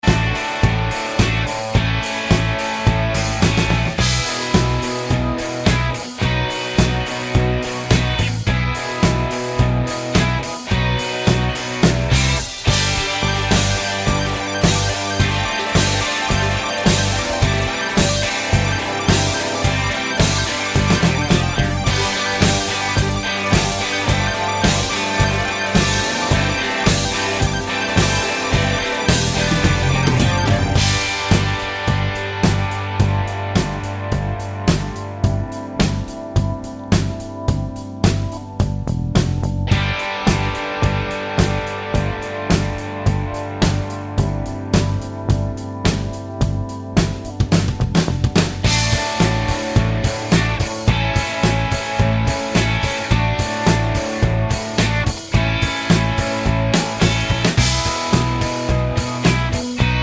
no Backing Vocals Soft Rock 3:29 Buy £1.50